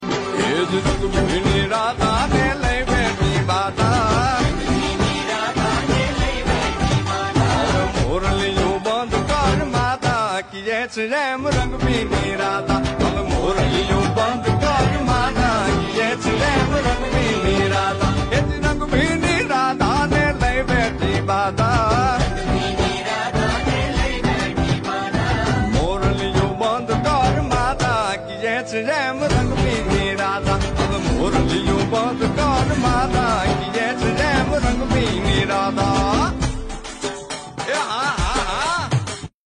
" a melodious ringtone that brings festive cheer.
Categories Indian Festival Ringtones